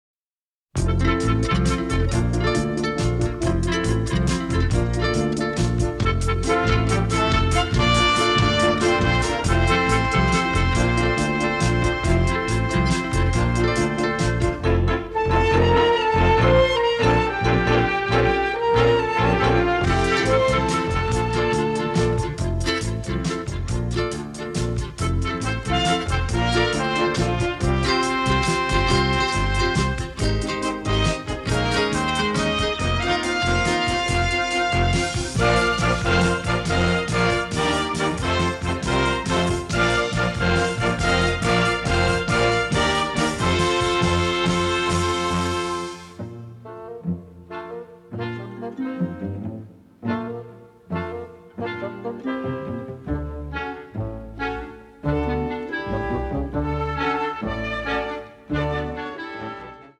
The music is descriptive, romantic and funny
All tracks stereo, except * mono